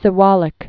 (sĭ-wälĭk)